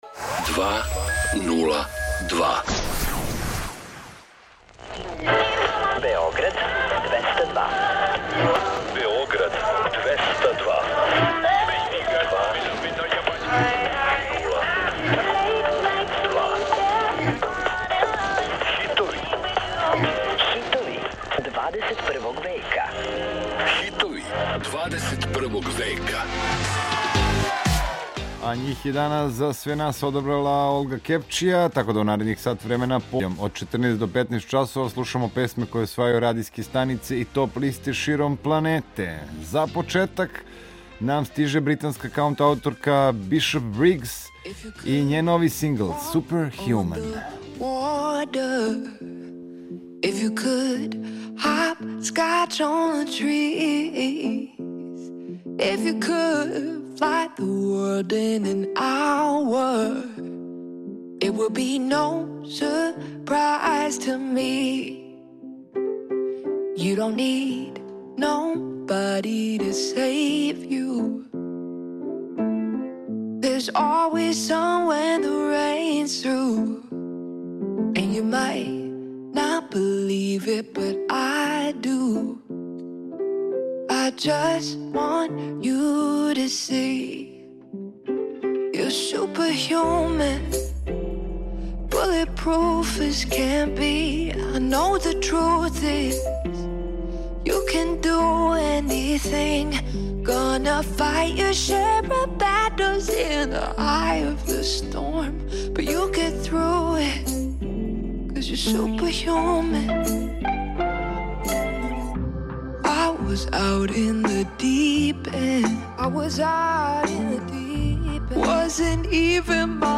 Чућете песме које се налазе на врховима светских топ листа.